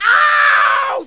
1 channel
ow!!!.mp3